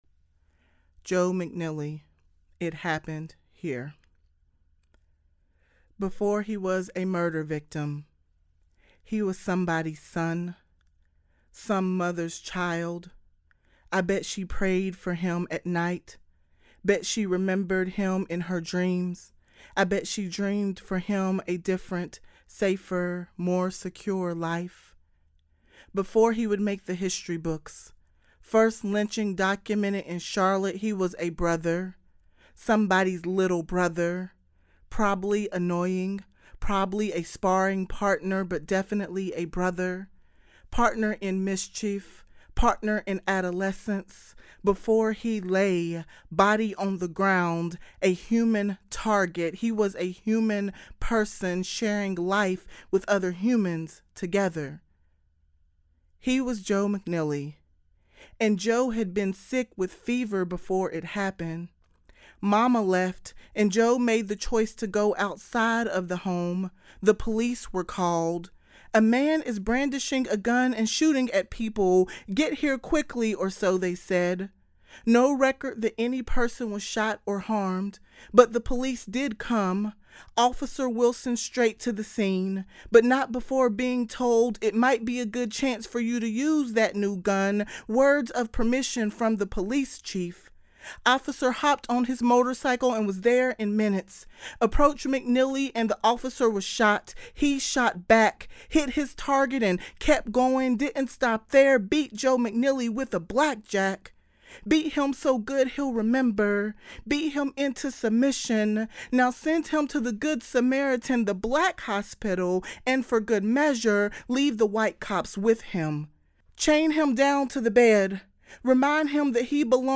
A spoken word piece